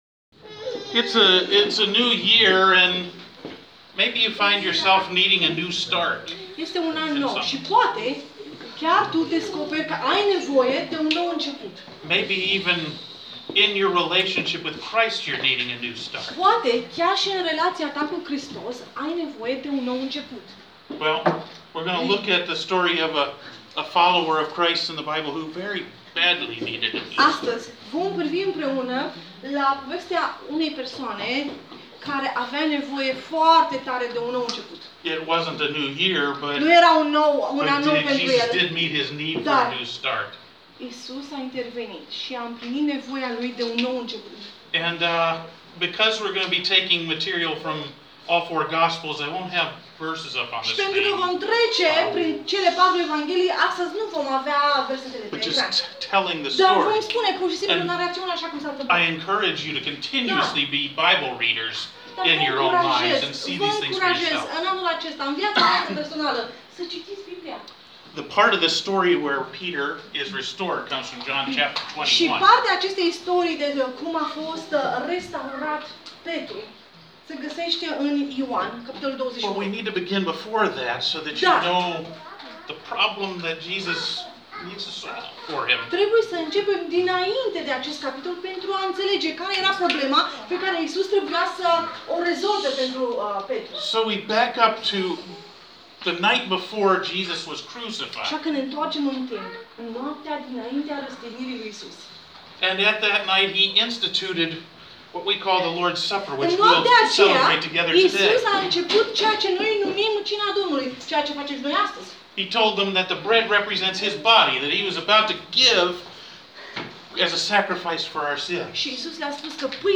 Mesaj Biblic – 05/01/20 – Un nou început